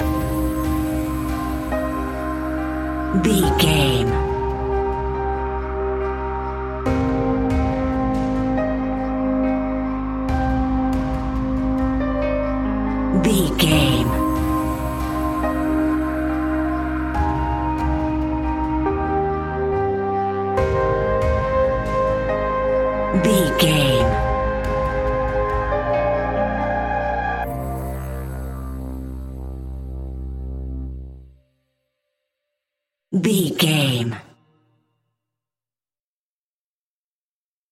In-crescendo
Thriller
Aeolian/Minor
ominous
haunting
eerie
piano
strings
synthesiser
percussion
brass
horror music